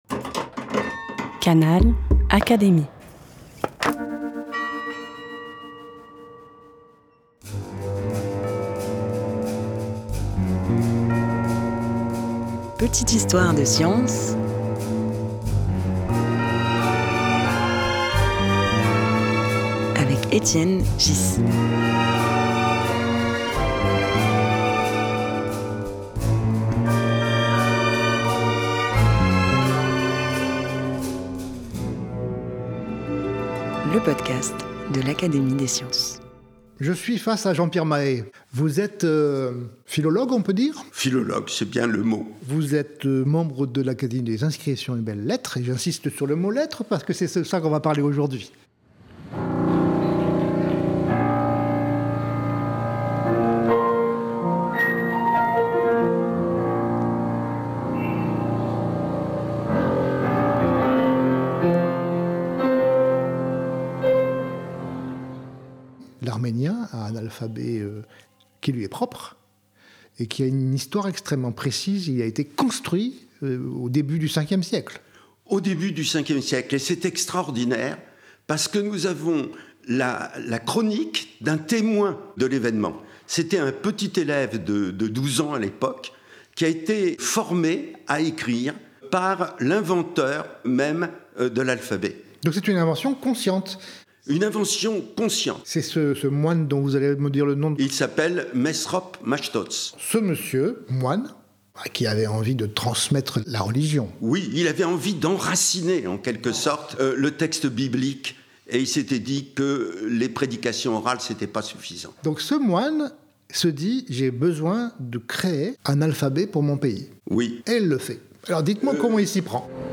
Étienne Ghys reçoit Jean-Pierre Mahé, philologue et membre de l’Académie des inscriptions et belles-lettres, pour raconter la naissance d’un alphabet